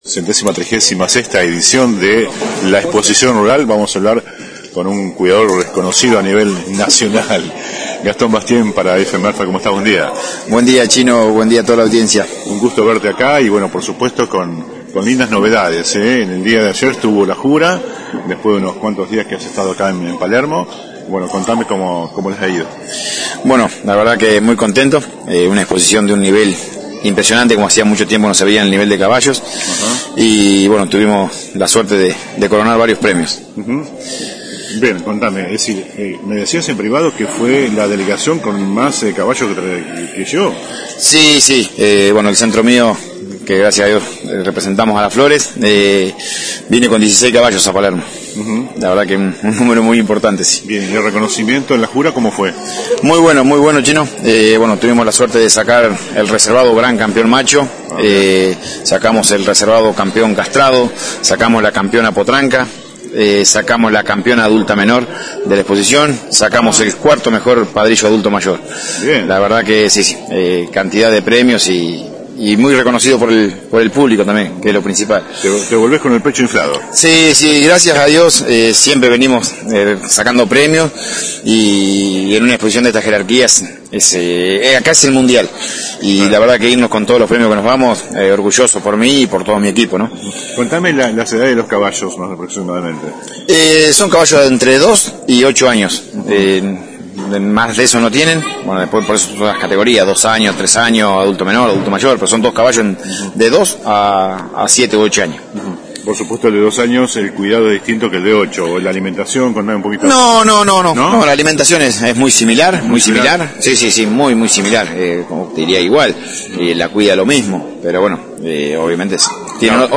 (galería de imágenes) La 91.5 se encuentra este fin de semana en la Exposición Rural de Palermo acompañando a los expositores florenses en los últimos dos días de la mayor muestra del campo a nivel nacional. Como hecho destacado, el Centro de Cría de Caballos «El Progreso», ubicado en nuestro partido, obtuvo nada menos que cuatro primeros premios.